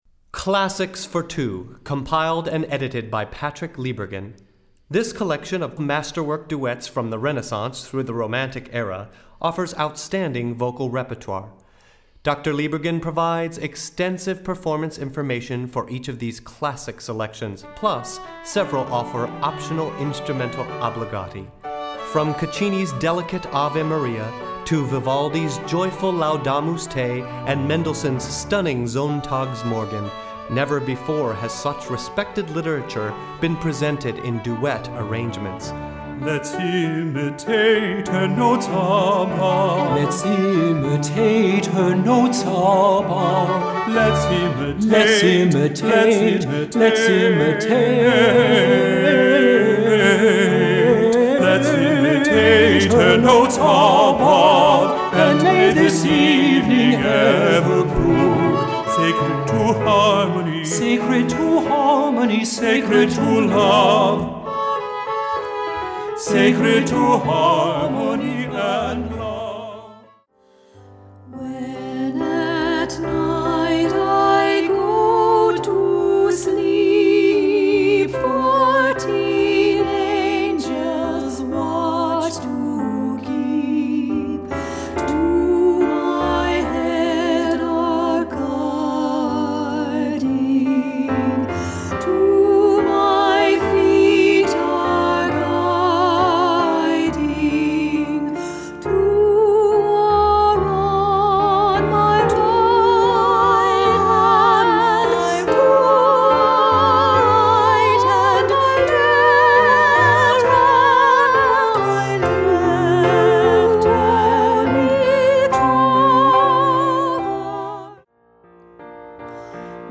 Voicing: Vocal Duet